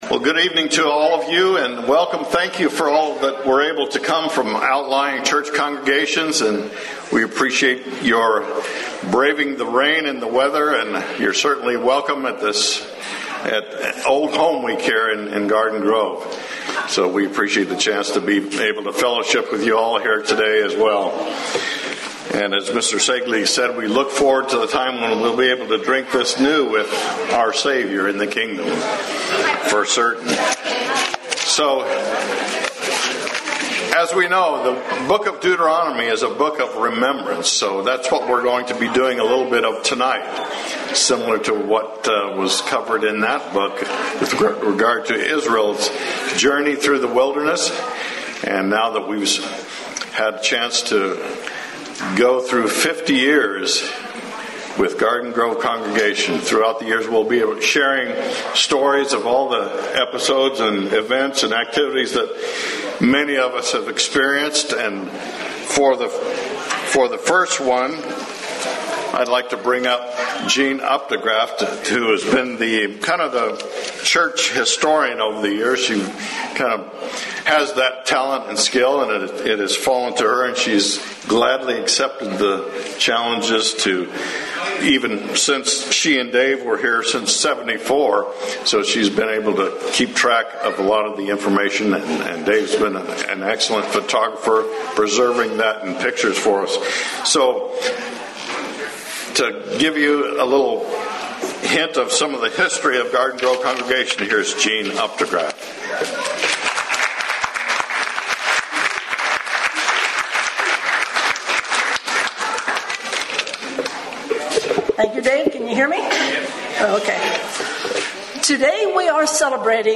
This event was a potluck dinner after the Sabbath Service followed by personal accounts, highlights and the movements of the Church around Orange County. Seven persons spoke at the 50th anniversary of the Church in Garden Grove, CA. The speakers were